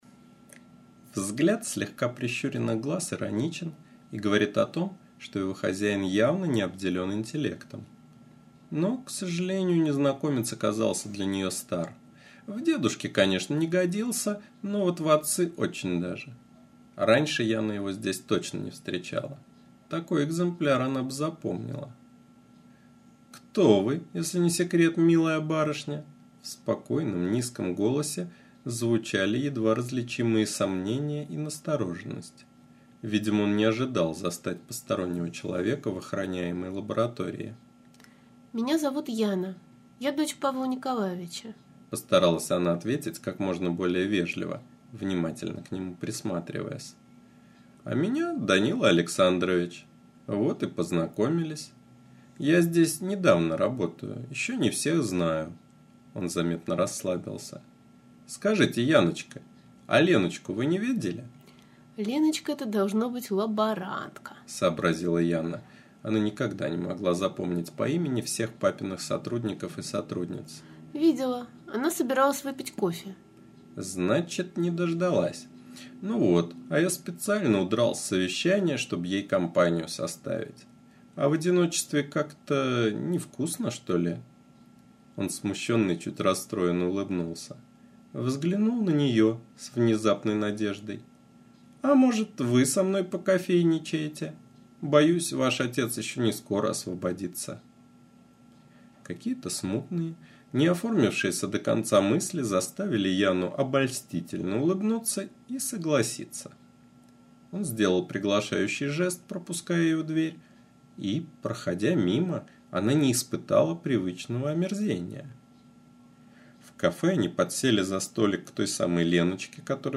Аудиокнига "Nе наша планета", .mp3